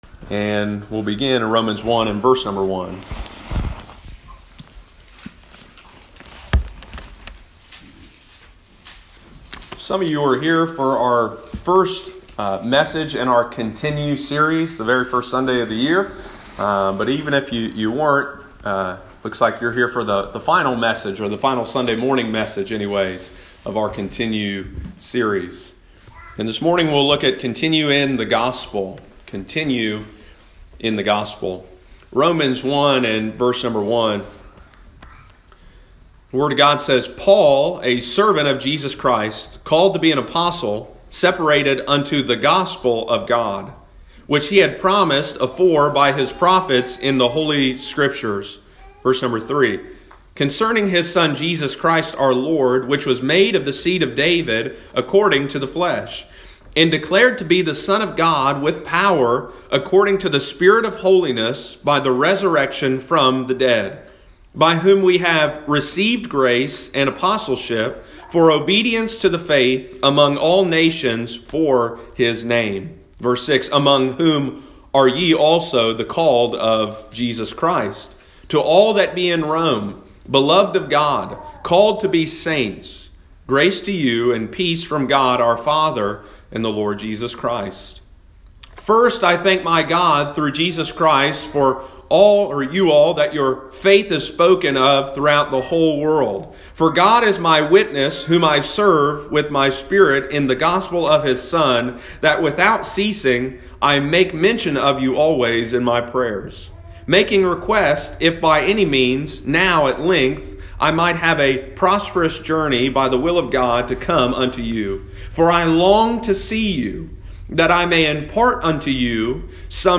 Continue in the Gospel – Lighthouse Baptist Church, Circleville Ohio